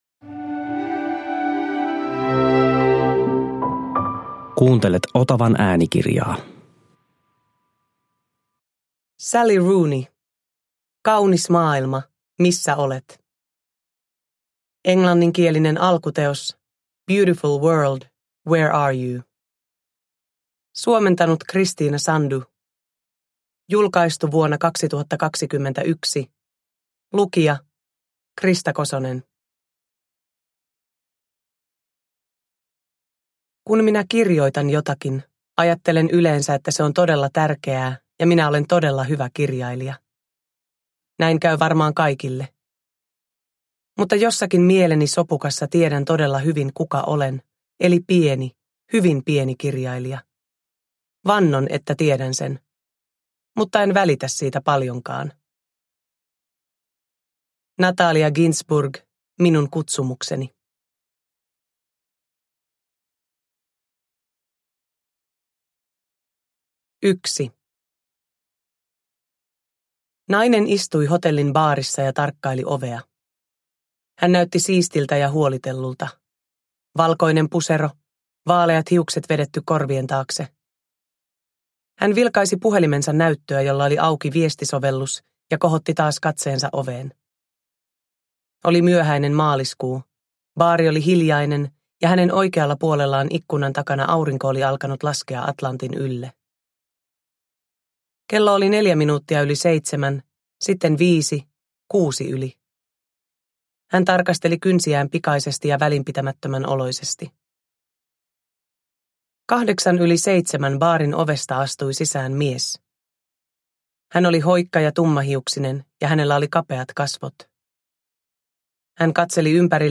Kaunis maailma, missä olet – Ljudbok – Laddas ner
Uppläsare: Krista Kosonen